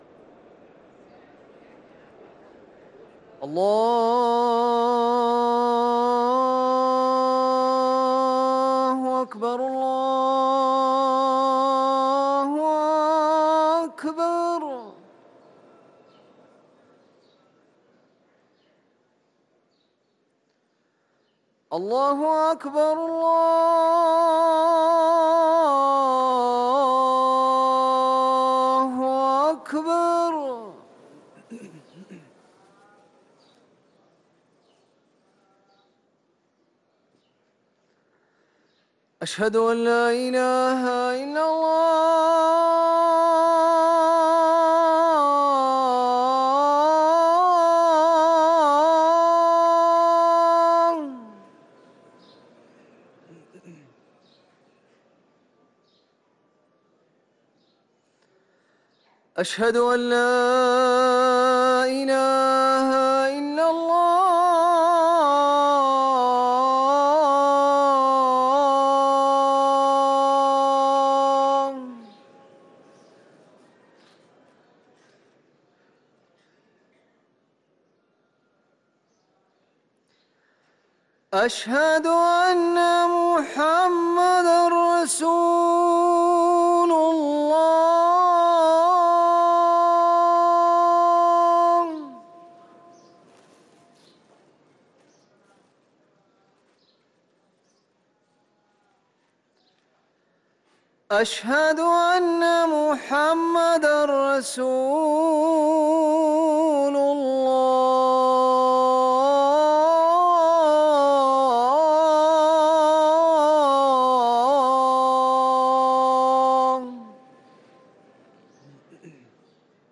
ركن الأذان 🕌